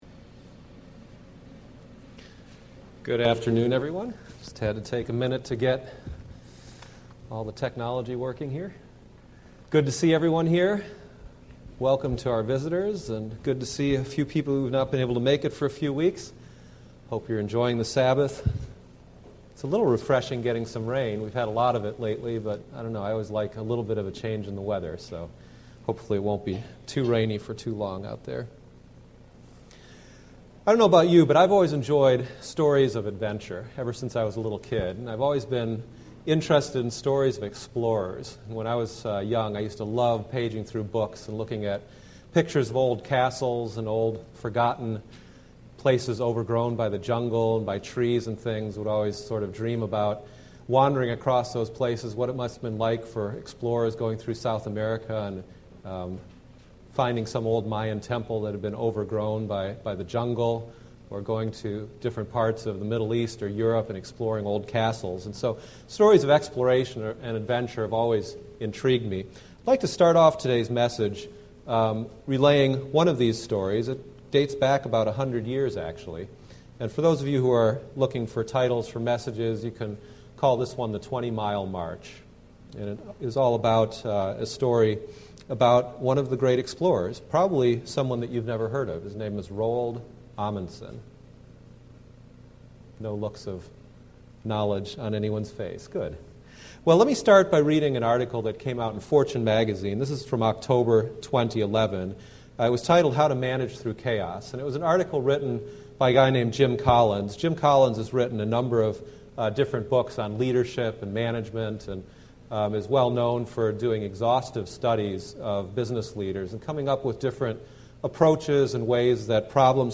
This sermon analyzes the the famous 20 mile march between a team led by Roald Amundsen, and another team led by Robert Falcon Scott by contrasting their journeys in 1910 to be the first to the South Pole. The behaviors and disciplines between these two teams teaches us the difference between success and tragedy.